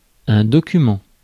Ääntäminen
France: IPA: /dɔ.ky.mɑ̃/